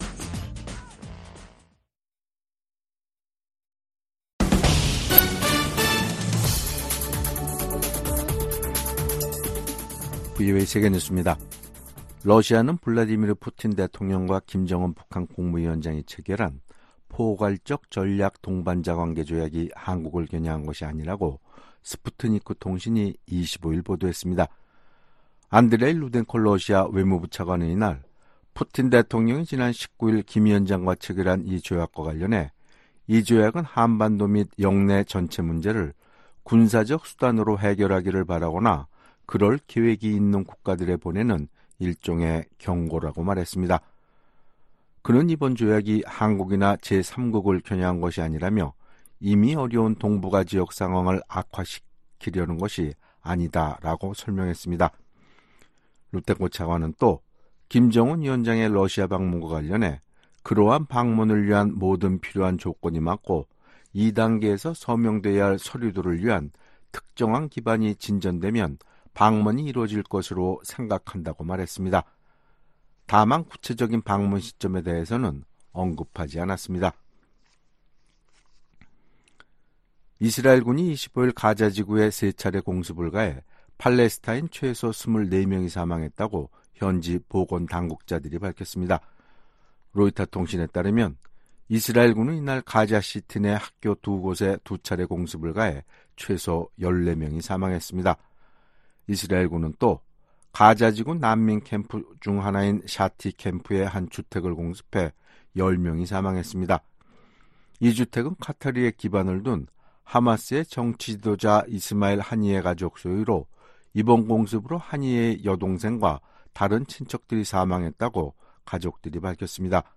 VOA 한국어 간판 뉴스 프로그램 '뉴스 투데이', 2024년 6월 25일 3부 방송입니다. 한국에 제공하는 미국의 확장억제 강화는 워싱턴 선언 이행으로 적절한 수준이라고 커트 캠벨 미 국무부 부장관이 말했습니다. 북한이 한국에 또 다시 '오물 풍선'을 살포하고 새로운 방식의 군사 도발 가능성도 내비쳤습니다.